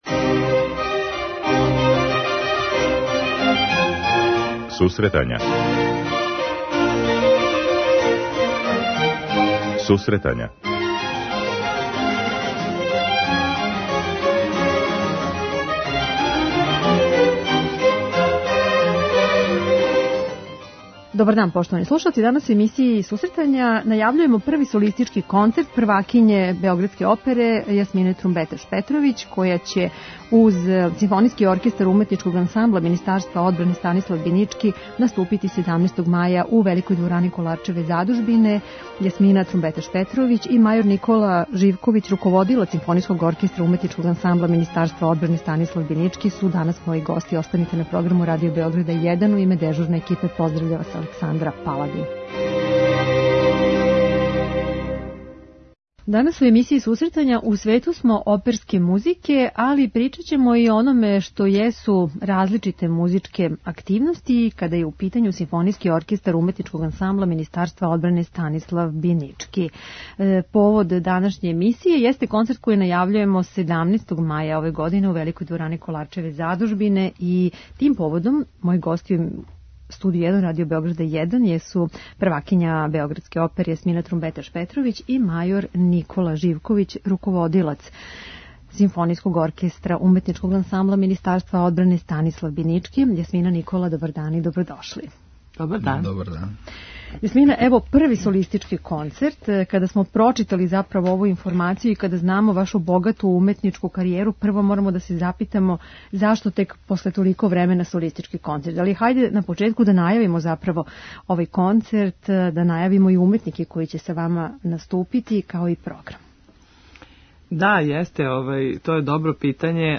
преузми : 10.77 MB Сусретања Autor: Музичка редакција Емисија за оне који воле уметничку музику.